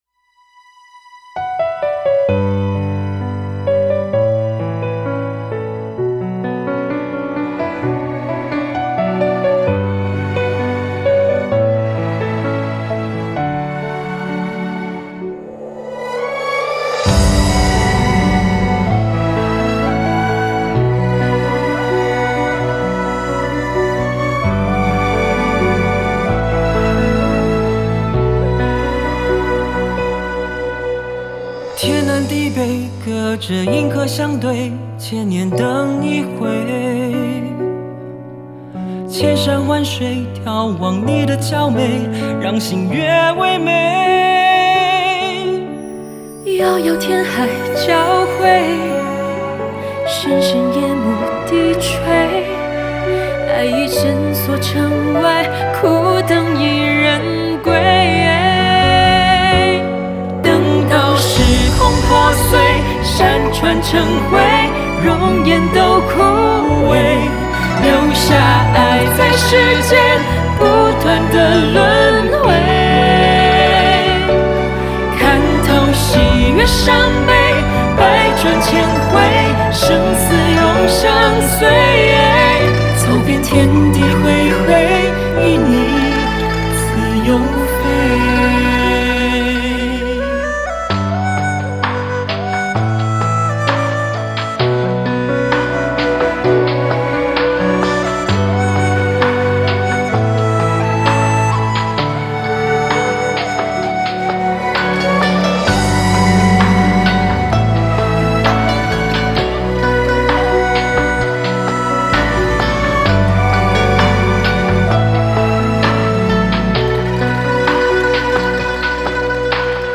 Ps：在线试听为压缩音质节选，体验无损音质请下载完整版
吉他
笛子
箫
琵琶
弦乐
和声